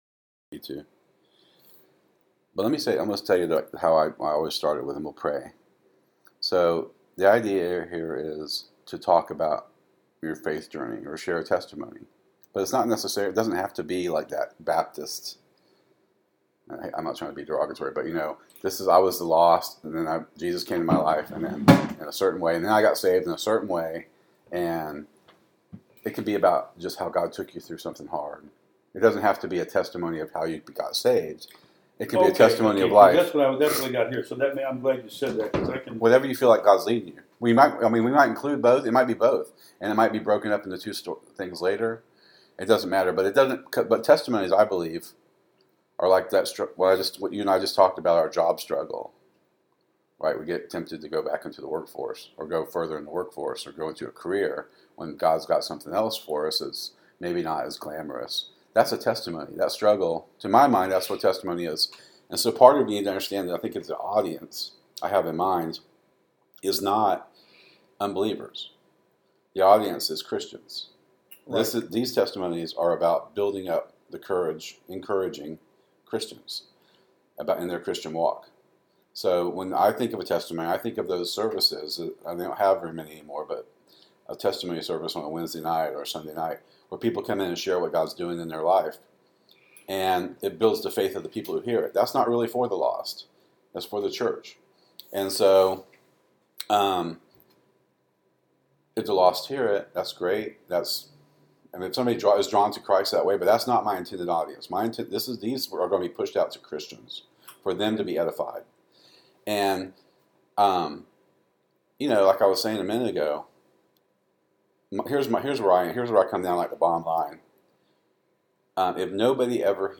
An amazingly honest conversation with a godly man, who’s life has brought him much success, more pain and even more Grace.